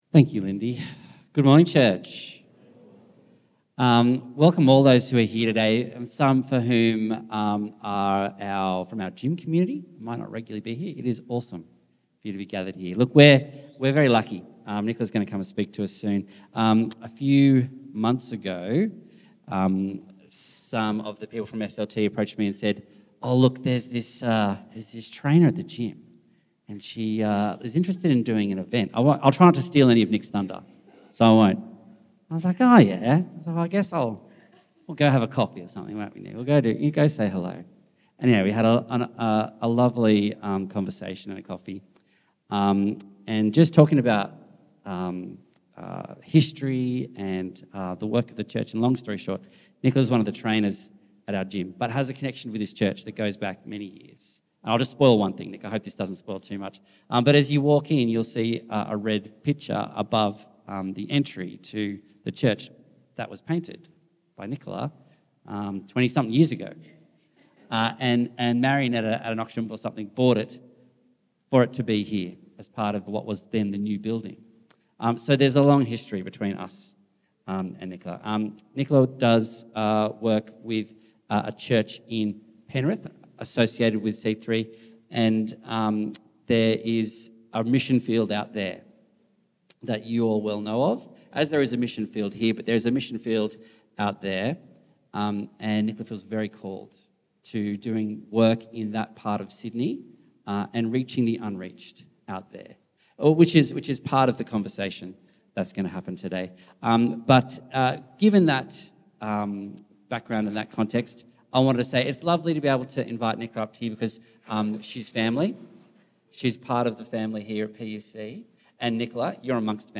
October Messages Week 2 Join us this month with a series of guest speakers and testimonies, looking at a variety of topics and biblical passages.